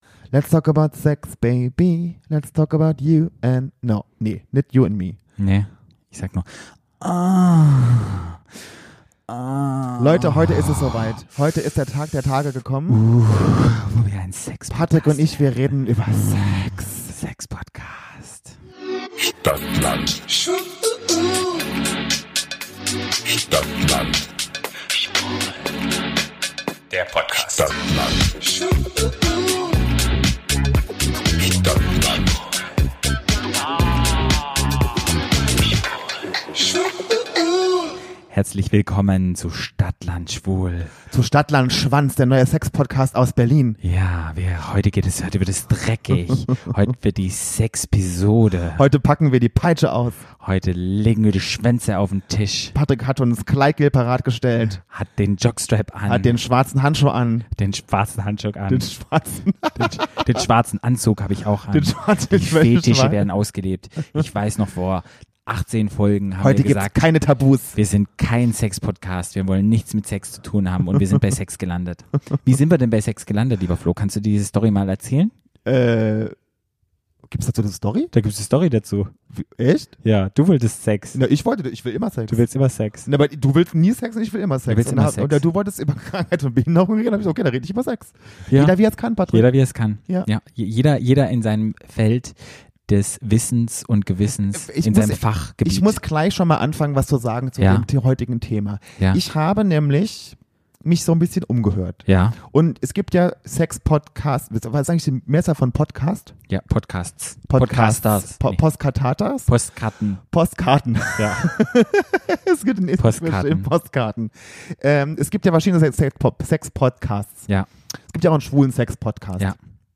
Wir schauen uns die Queens, die Hosts und die Gastjury genau an.